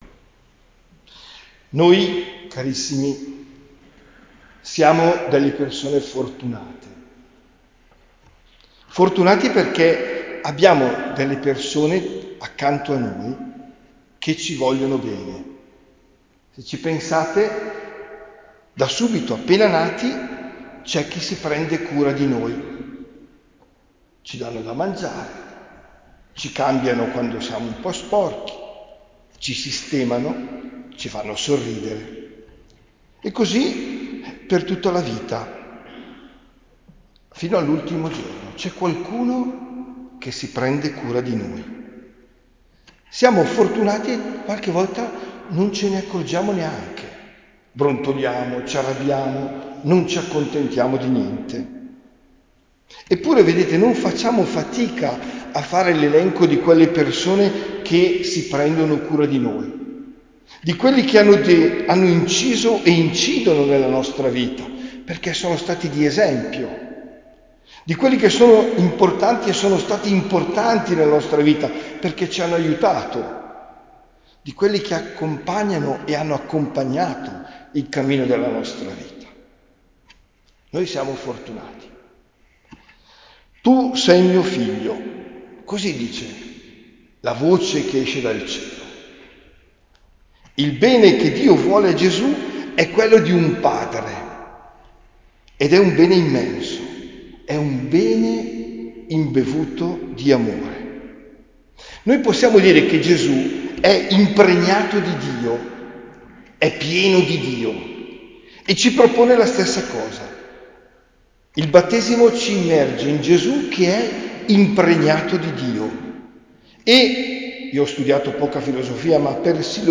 OMELIA DEL 12 GENNAIO 2025